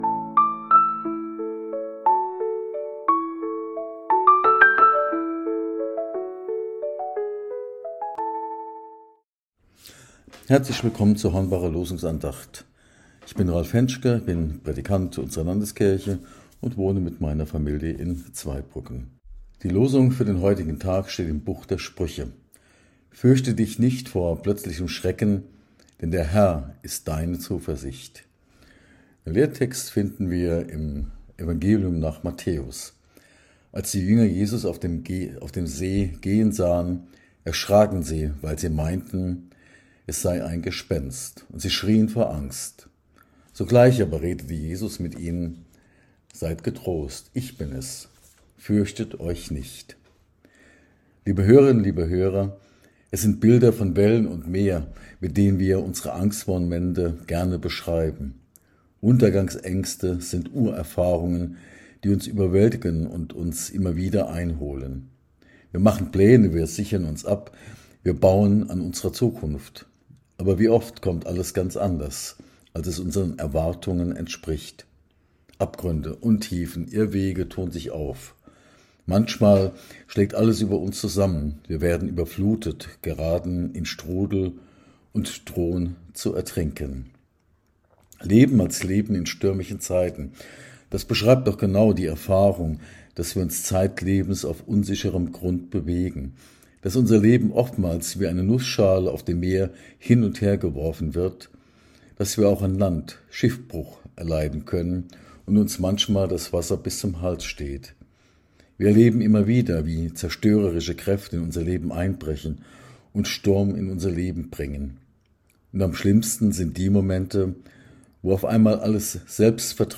Losungsandacht für Freitag, 12.09.2025